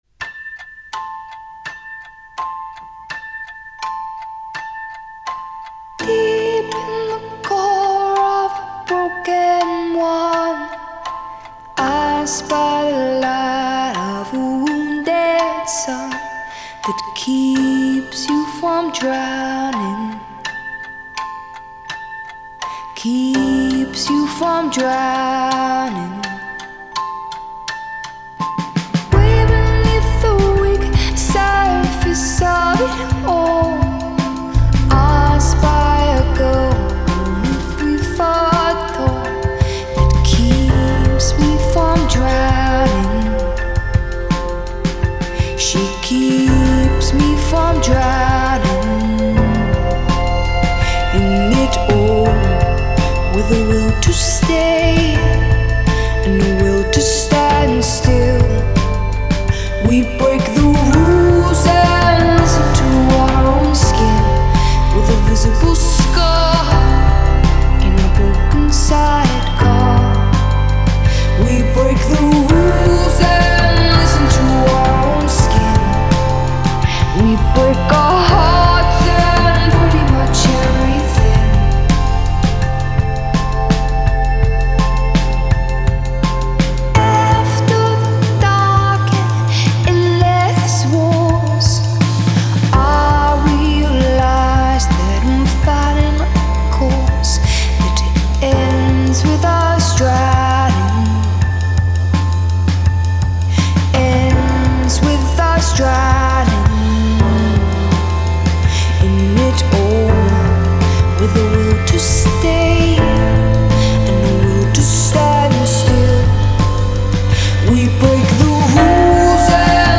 moody, w/ simmering momentum
with an elastic tone that ranges from sweet to muscular.
softly-voiced piano chords.